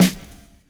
Medicated Snare 32.wav